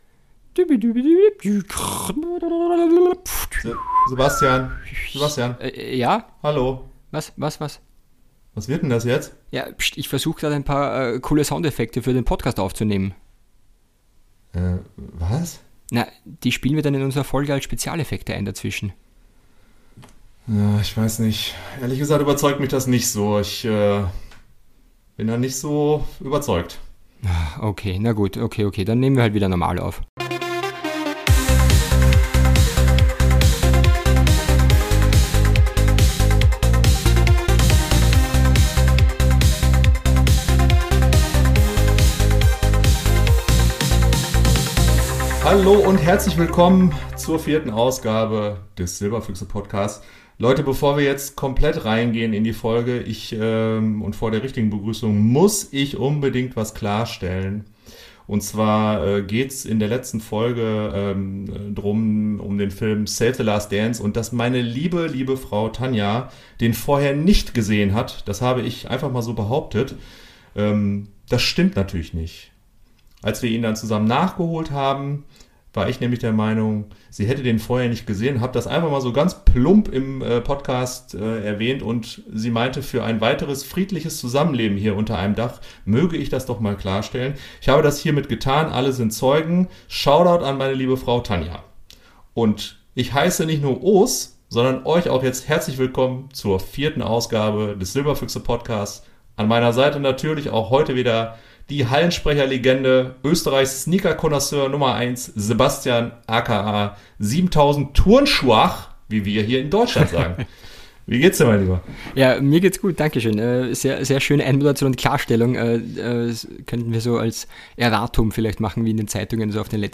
Natürlich darf auch ein Trip in die Videothek nicht fehlen und Musik gibt's auch wieder auf die Ohren.